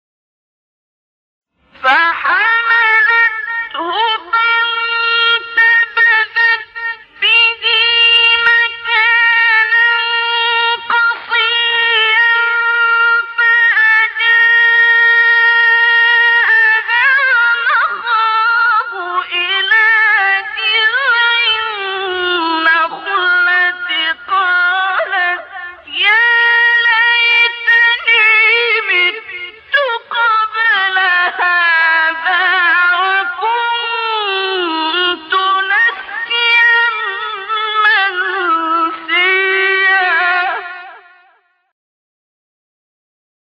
سایت-قرآن-کلام-نورانی-حجاز-عبدالباسط-4.mp3